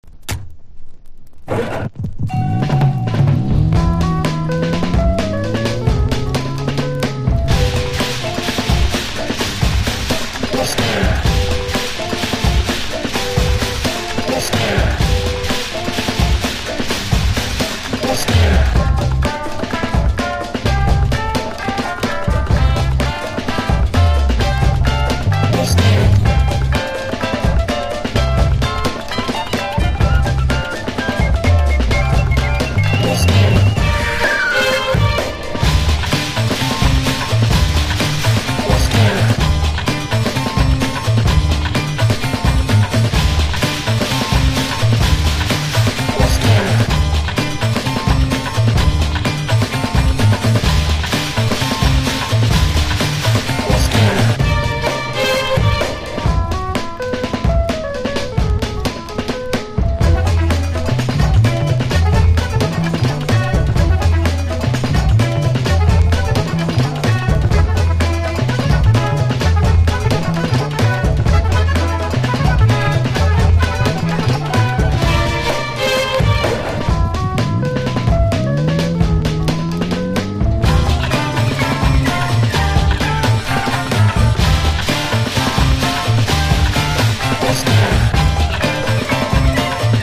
90-20’S ROCK# BREAK BEATS / BIG BEAT
今回は60`Sサンプリング！